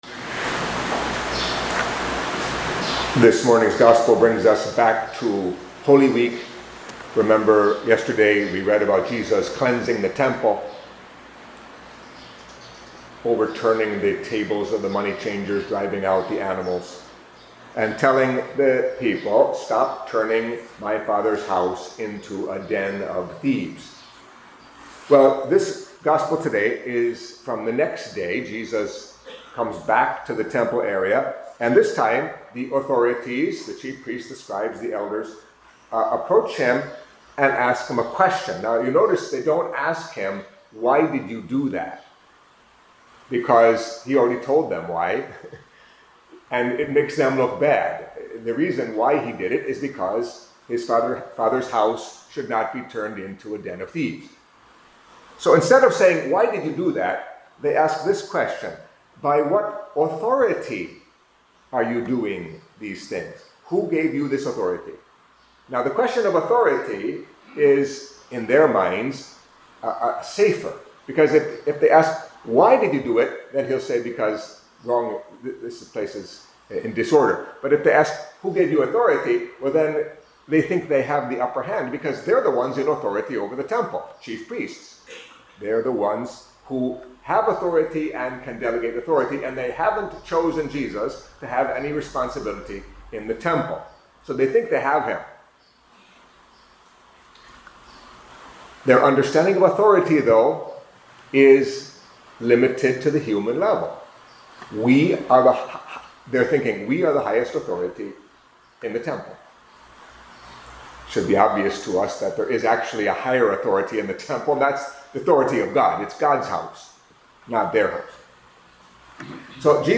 Catholic Mass homily for Saturday of the Eighth Week in Ordinary Time